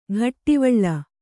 ♪ ghattivaḷḷa